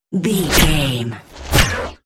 Whoosh fast flap flash
Sound Effects
Fast
futuristic
high tech
whoosh